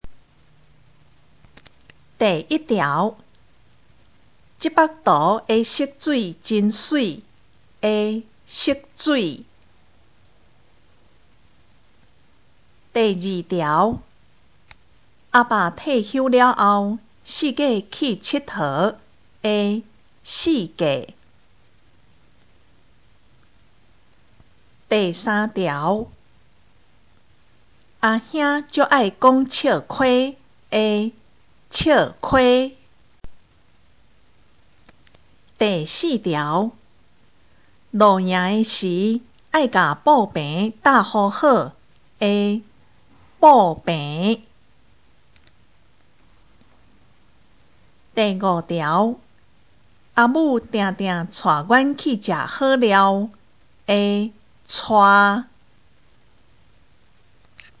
【國中閩南語2】單元評量(2)聽力測驗mp3